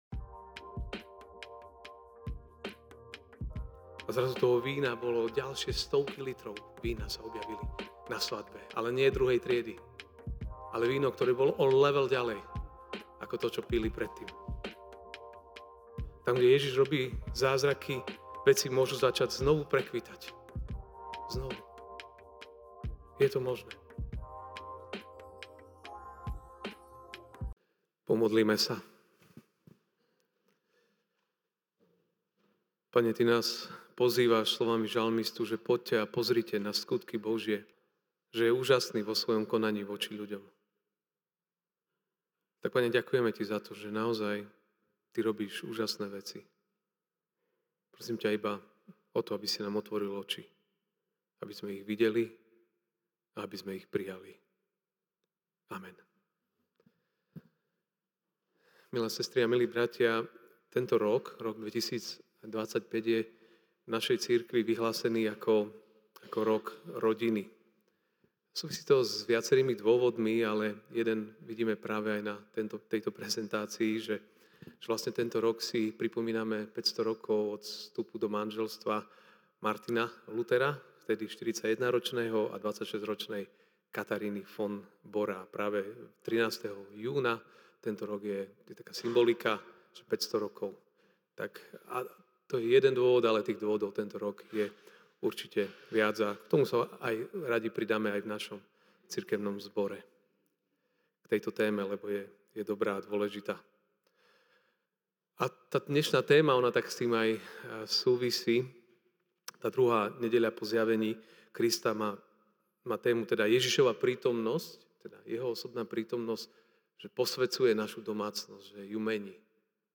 jan 19, 2025 Jeho prítomnosť, tvoja nádej MP3 SUBSCRIBE on iTunes(Podcast) Notes Sermons in this Series Ranná kázeň: J(2, 1-12) „ Na tretí deň bola svadba v Káne Galilejskej a bola tam matka Ježišova.